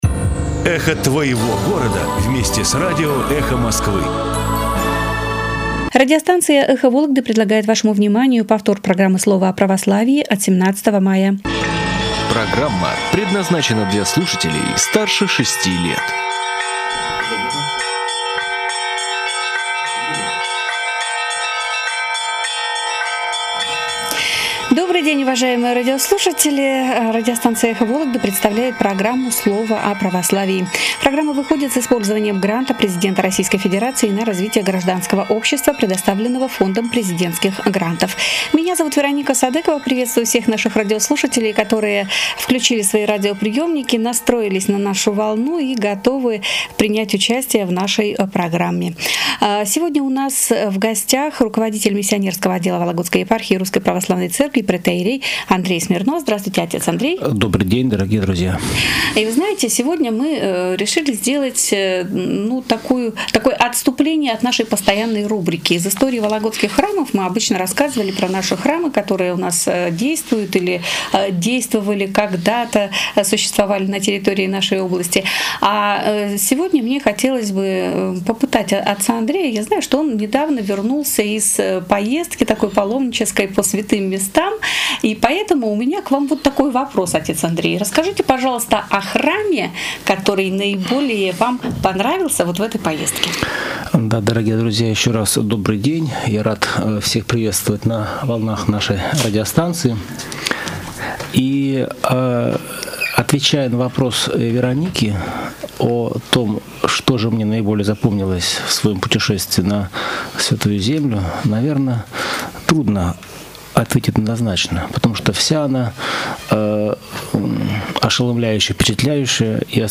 Руководители отделов Вологодской епархии выступили в прямом эфире радио "Эхо Вологды"